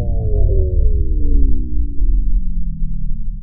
deactivate.wav